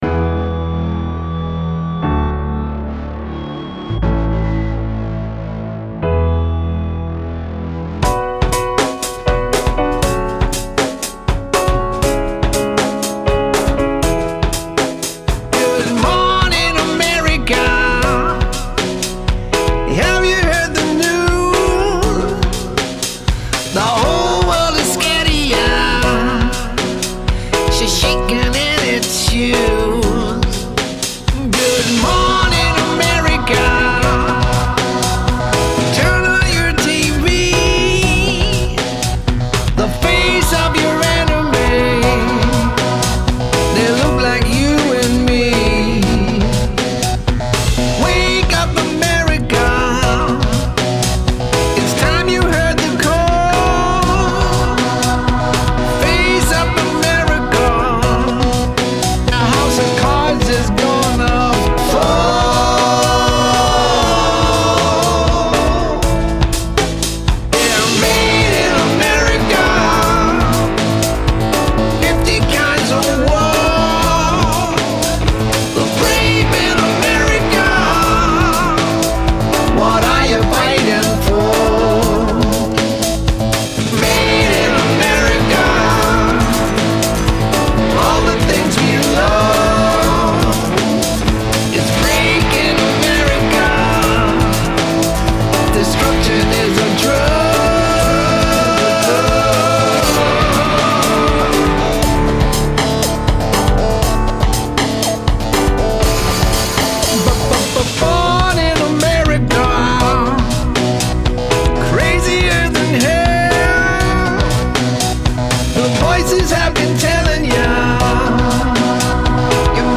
anti war song.